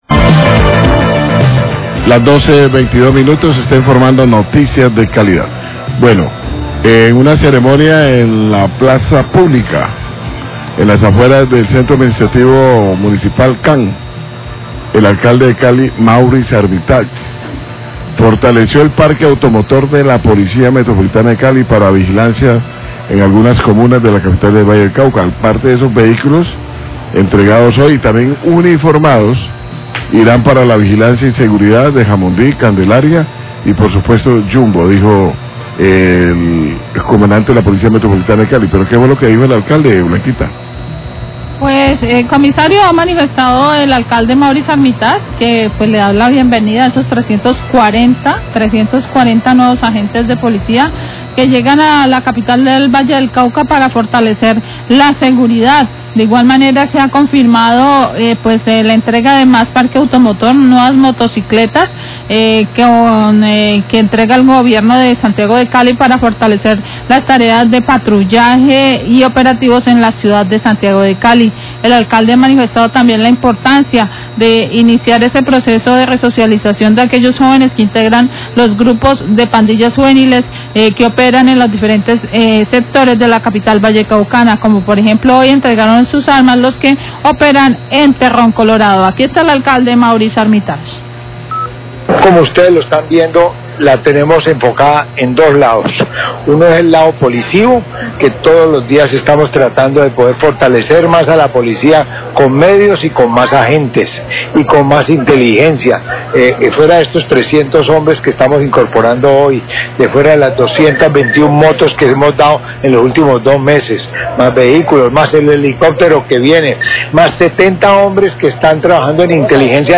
El alcalde de Cali en una ceremonia de bienvenida de 340 nuevos  uniformados entregó nuevo parque automotor, que llegan a reforzar la seguridad en la ciudad.